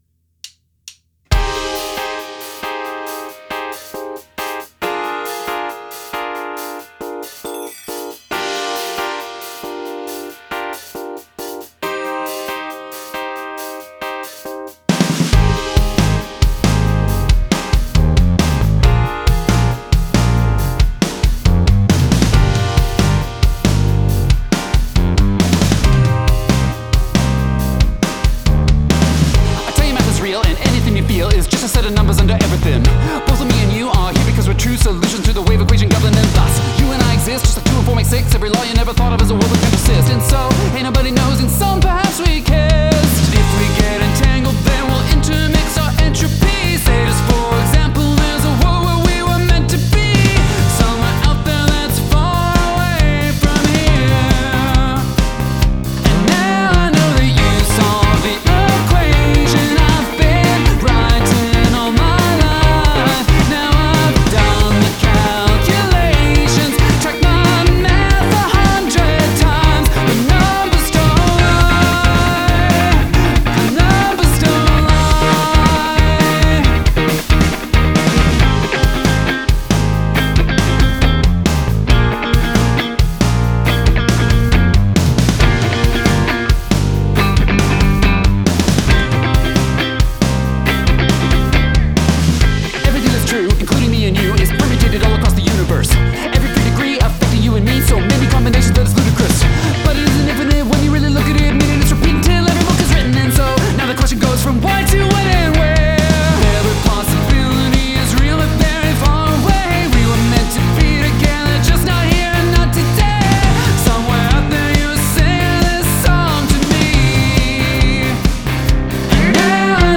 Use the Royal Road chord progression